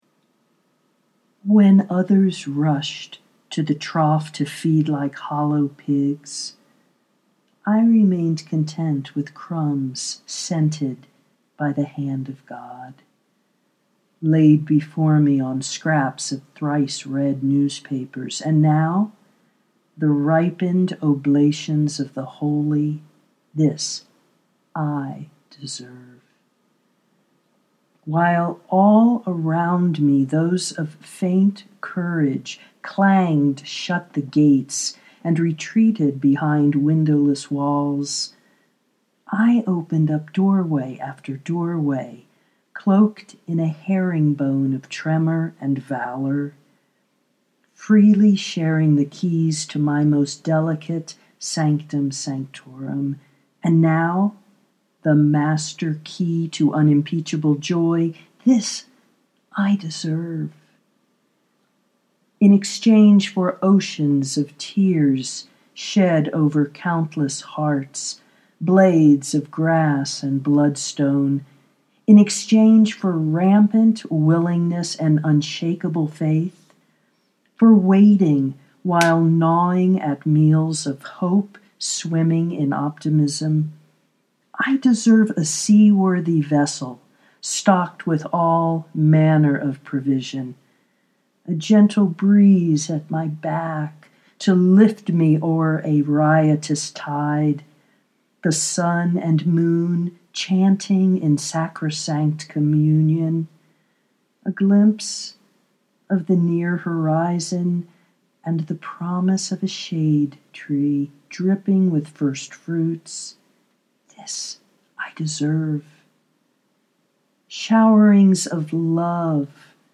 this i deserve: affirmation for the deeply worthy (audio poetry 3:50)